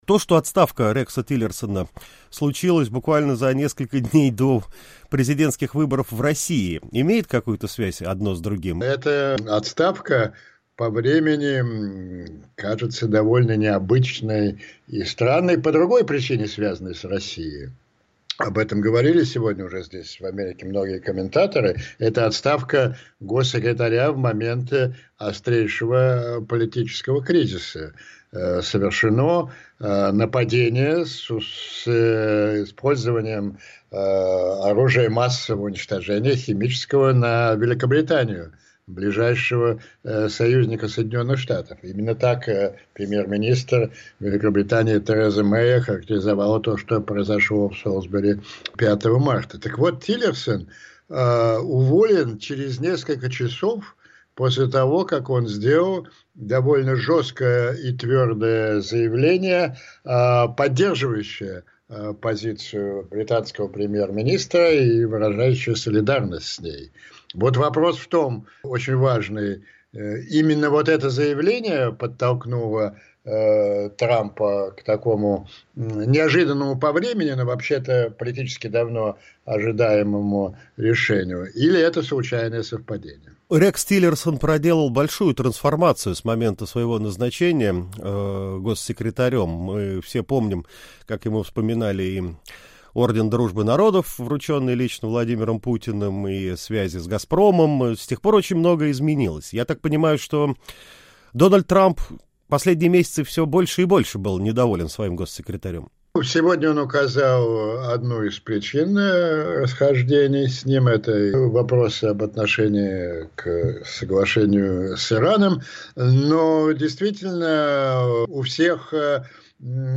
Политолог Андрей Пионтковский об отставке Рекса Тиллерсона